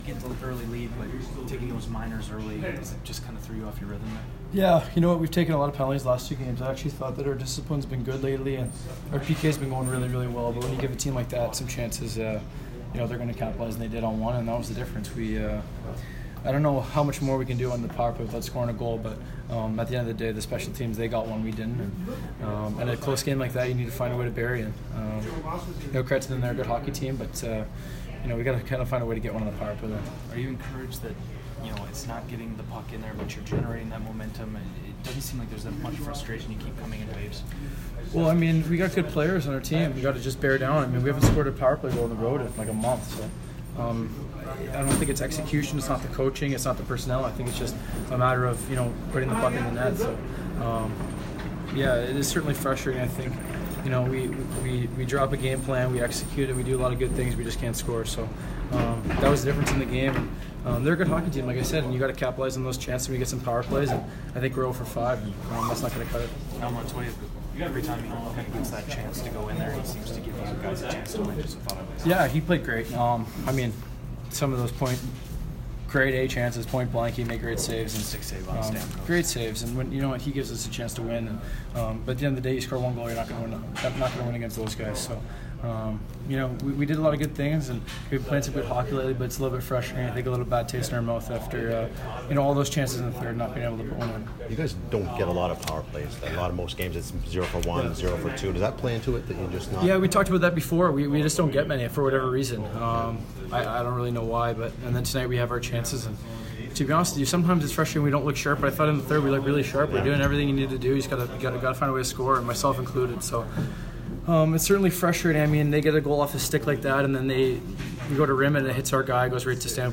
Ryan Strome post-game 3/18